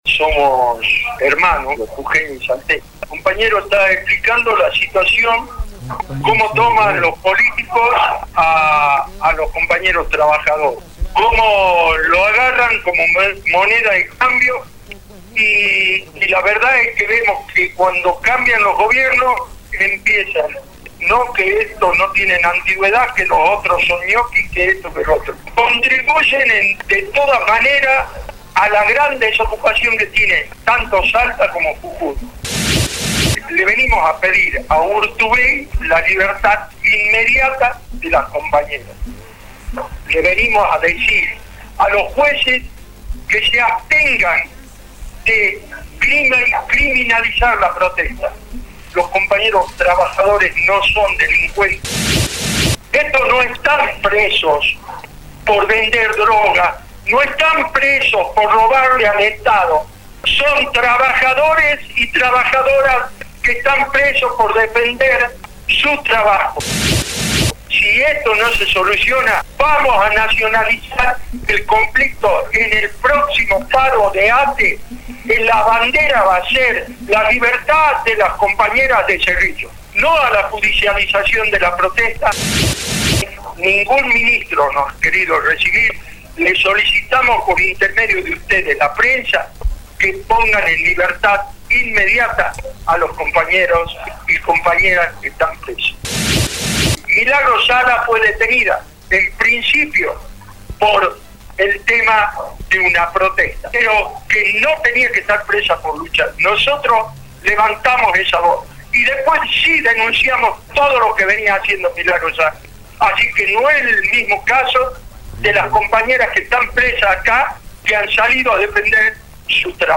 SANTILLAN-CONFERENCIA-DE-PRENSA.mp3